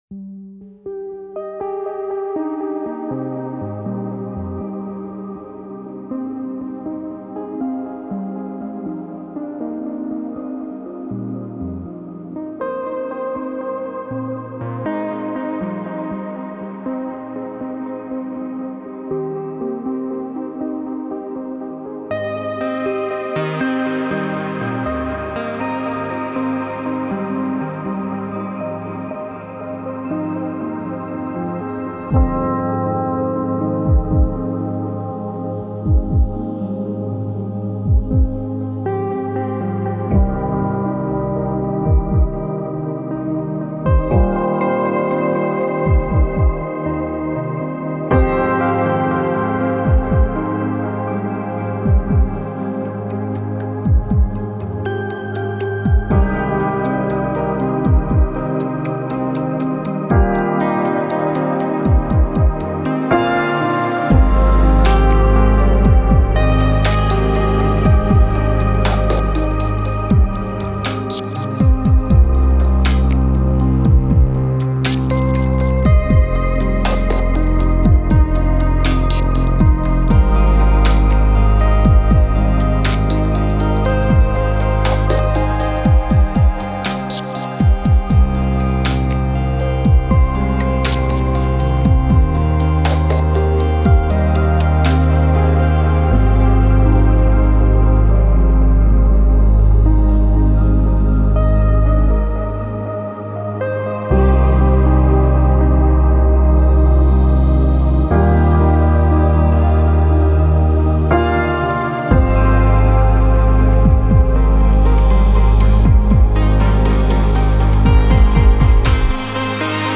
Synth heavy, reflective beat flies deep into space.